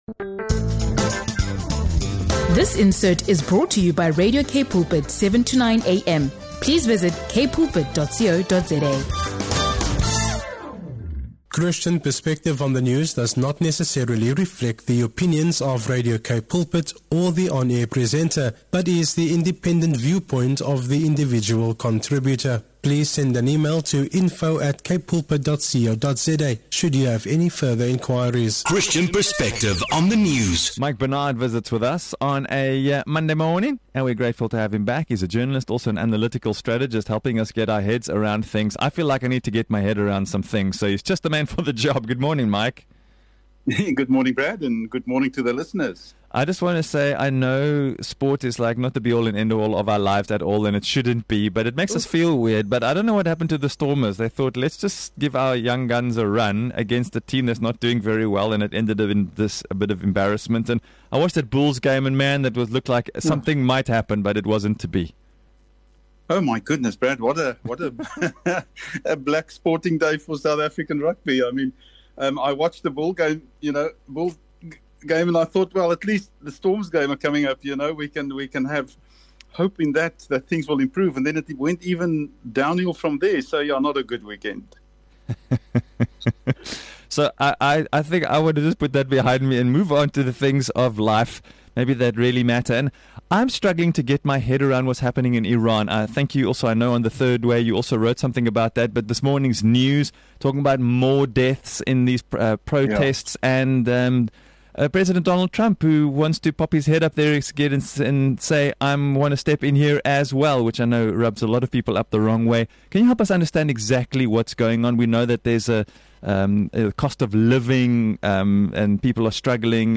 a powerful and insightful discussion on the unfolding crisis in Iran